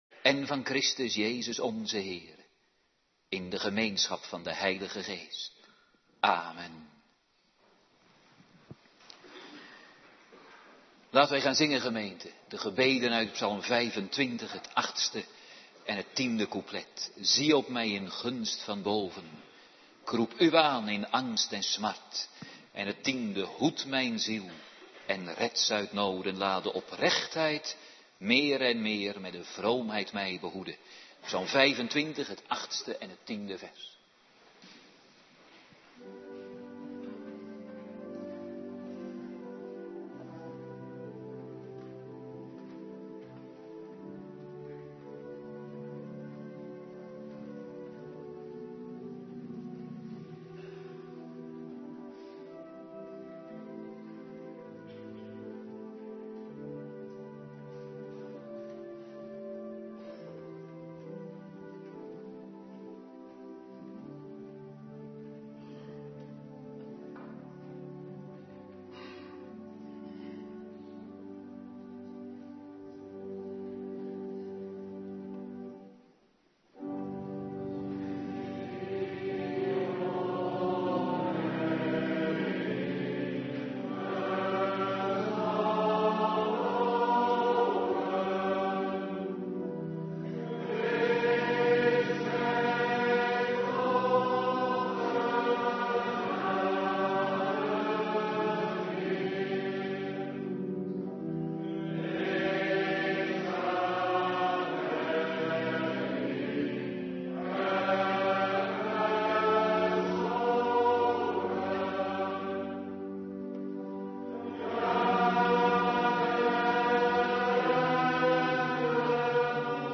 Avonddienst biddag
19:30 t/m 21:00 Locatie: Hervormde Gemeente Waarder Agenda